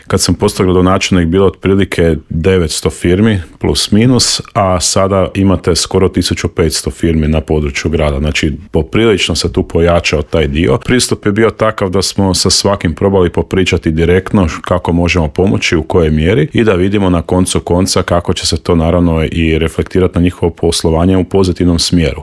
Kakva je ponuda i što sve čeka posjetitelje u Intervjuu Media servisa otkrio nam je tamošnji gradonačelnik Dario Zurovec.